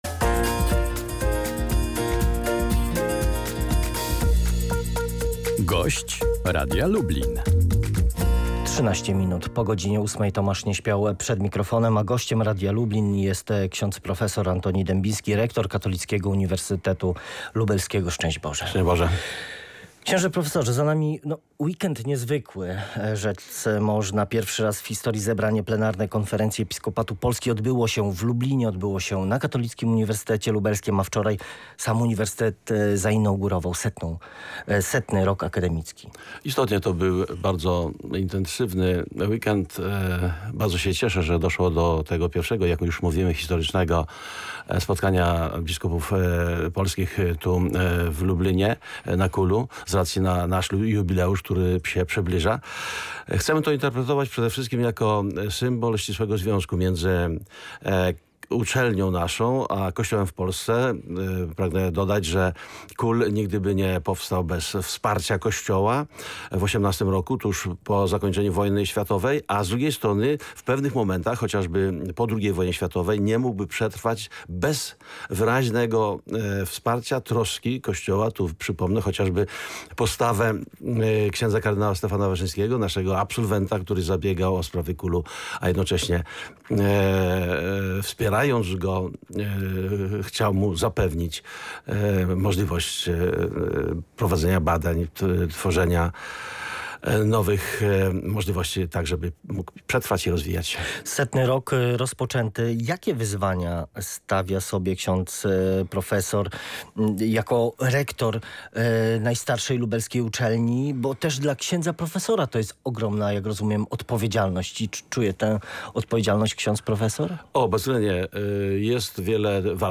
podczas rozmowy w studiu Polskiego Radia Lublin przyznał